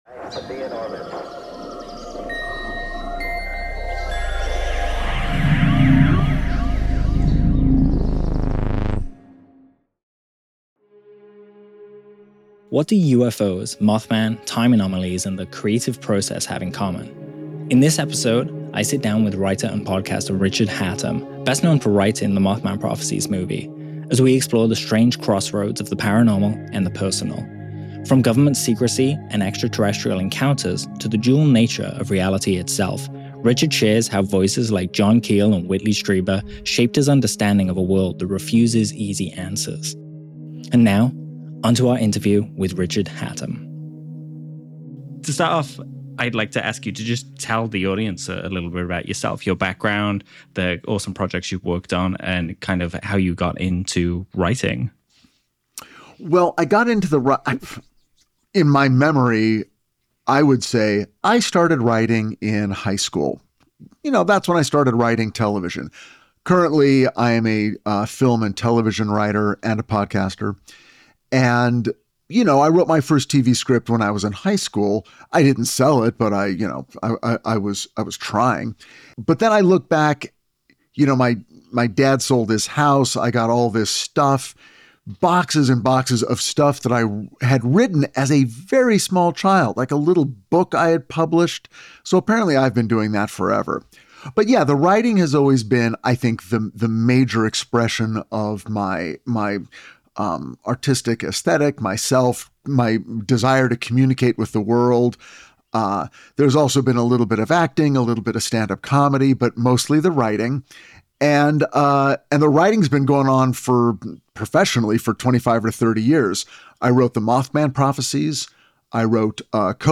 This episode offers a fascinating look at the intersection of creativity, mystery, and the human experience. Tune in for an engaging discussion that challenges our understanding of reality and the unknown.